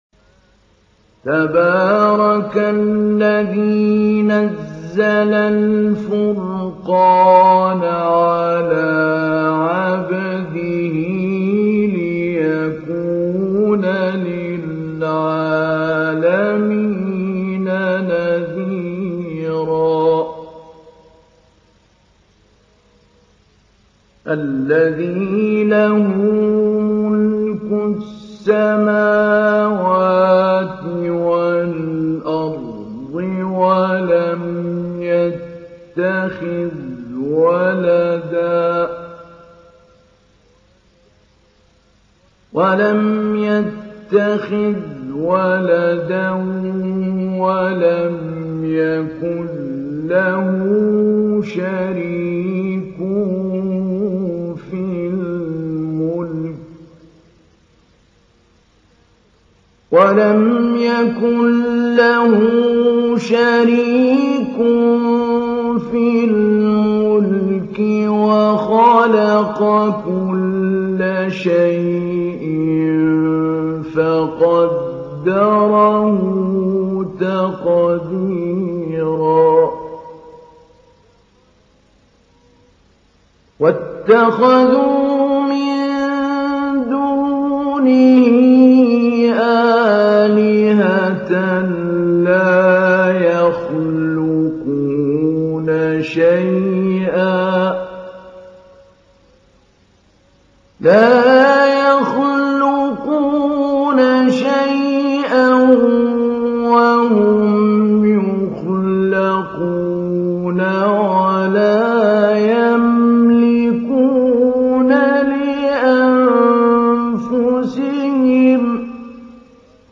تحميل : 25. سورة الفرقان / القارئ محمود علي البنا / القرآن الكريم / موقع يا حسين